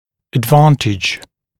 [əd’vɑːntɪʤ][эд’ва:нтидж]преимущество, польза, выгода